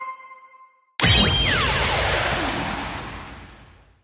轻触Start与cancel按钮.mp3